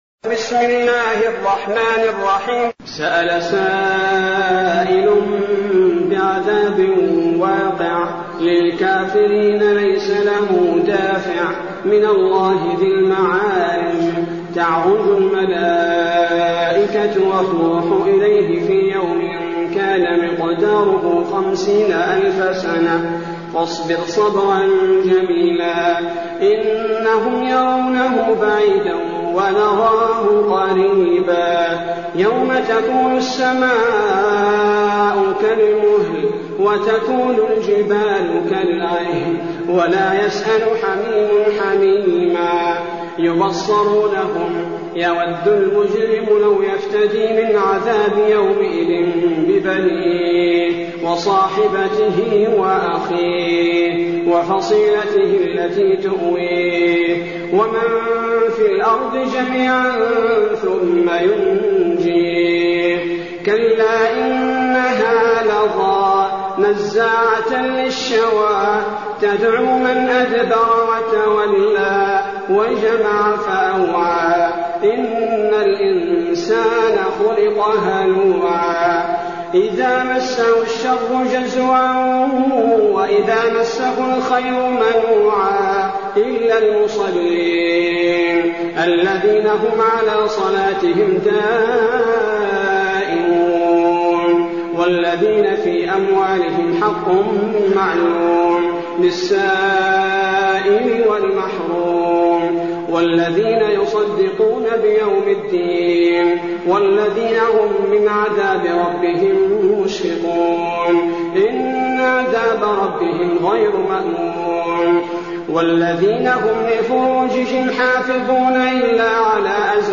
المكان: المسجد النبوي الشيخ: فضيلة الشيخ عبدالباري الثبيتي فضيلة الشيخ عبدالباري الثبيتي المعارج